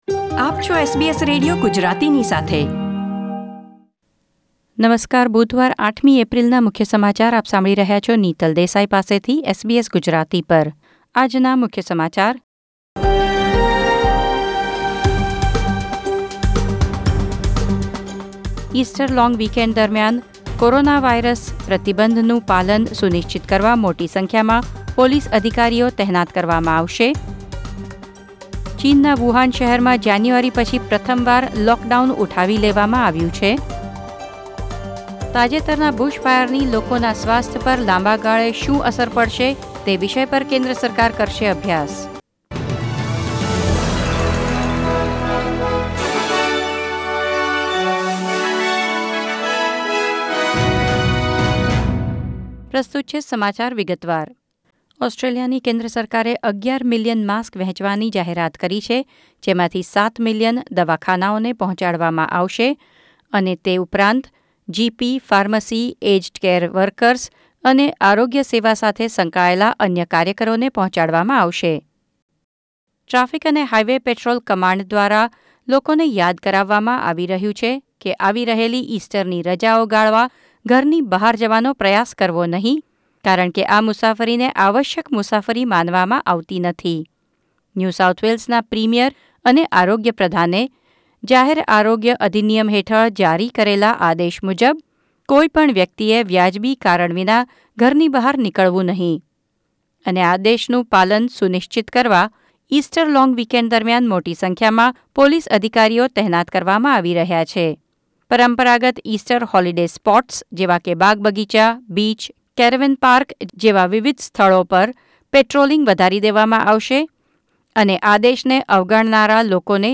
૮ એપ્રિલ ૨૦૨૦ ના મુખ્ય સમાચાર